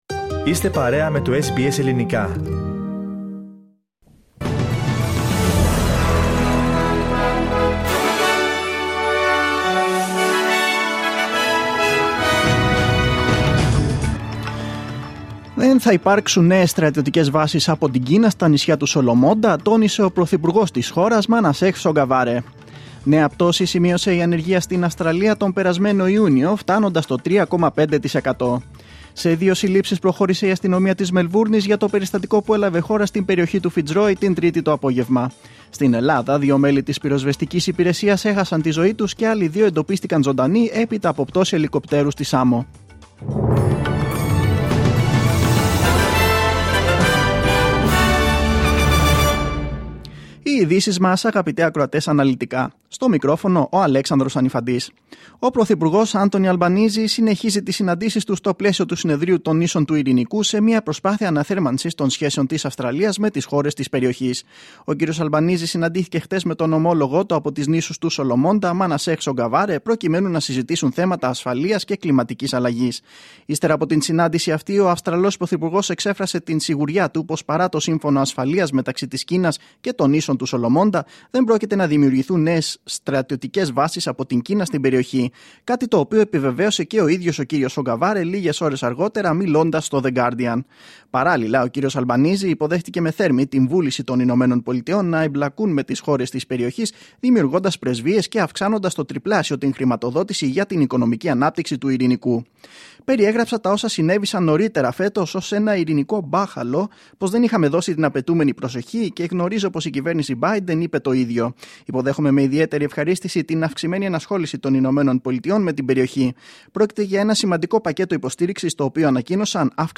Δελτίο Ειδήσεων Πέμπτη 14.7.2022